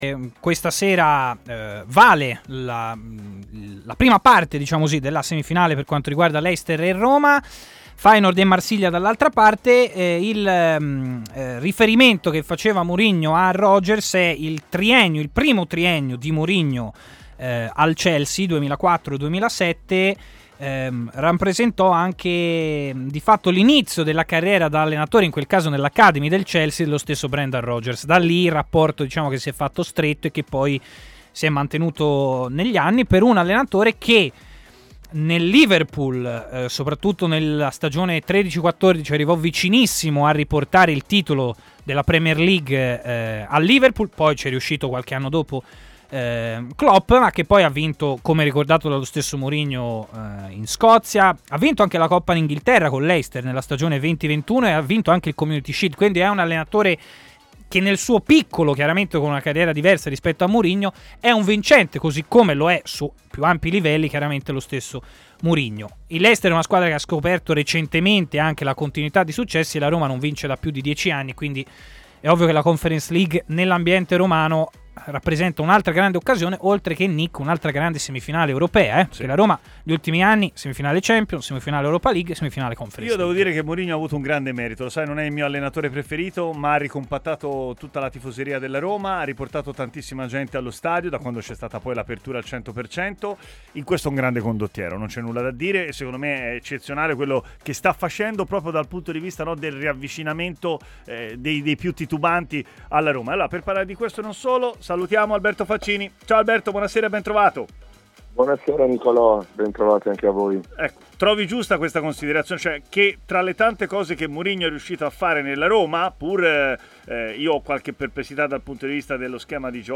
ha parlato in diretta su TMW Radio